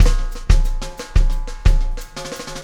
Extra Terrestrial Beat 02.wav